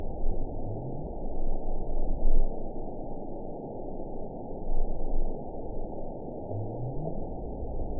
event 911241 date 02/19/22 time 00:54:40 GMT (3 years, 3 months ago) score 9.33 location TSS-AB01 detected by nrw target species NRW annotations +NRW Spectrogram: Frequency (kHz) vs. Time (s) audio not available .wav